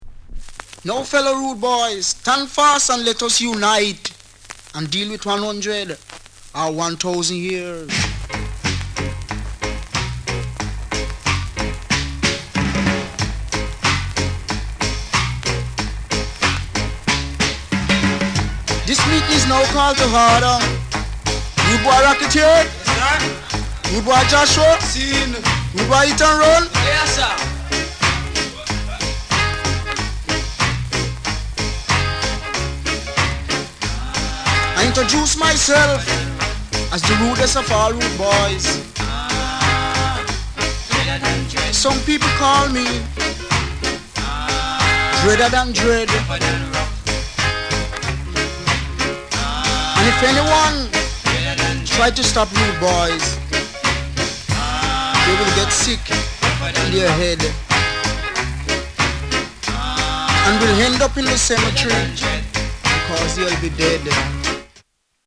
SOUND CONDITION A SIDE VG(OK)
RARE ROCKSTEAY